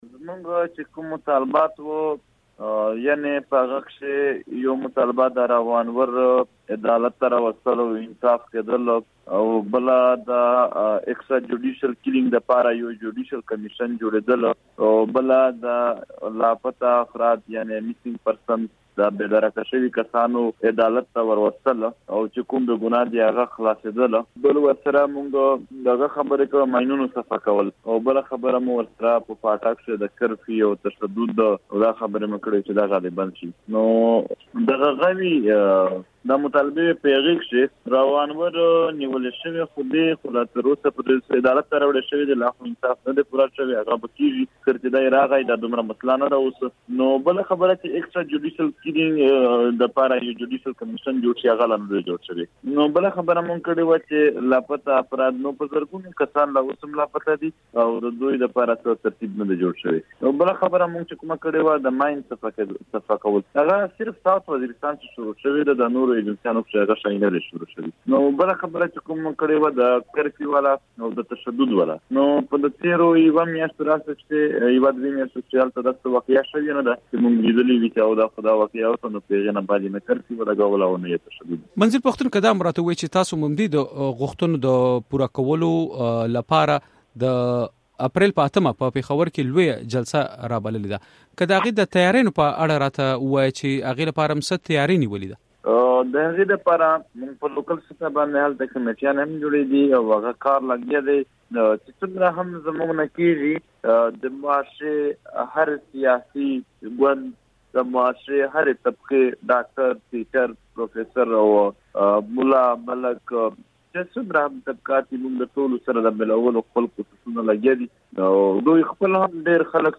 نوموړي دا څرګندونې له مشال راډیو سره په مرکه کې وکړې او زیاته یې کړه چې د پيښور جلسي لپاره تيارۍ روانې دي او خلک پخپله له دوي سره رابطې کوي.